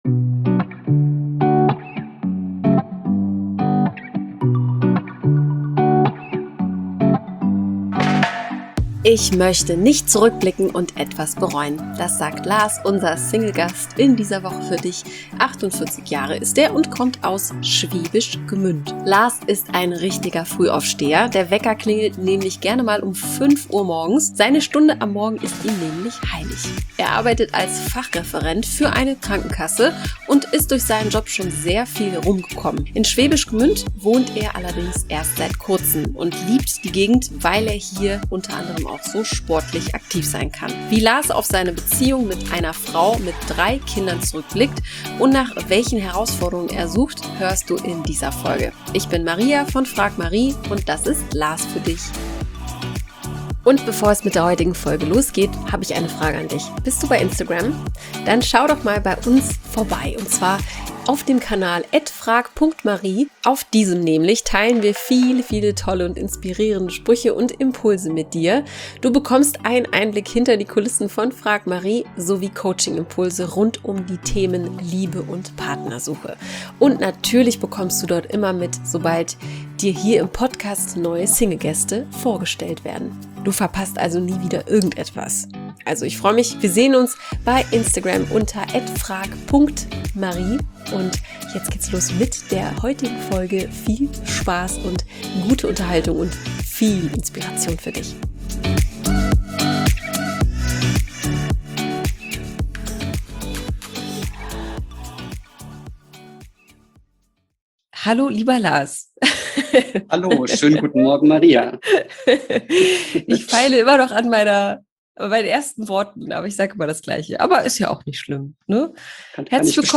Wir lassen Menschen in einem lockeren und inspirierenden Gespräch zu Wort kommen, die offen für die Liebe sind.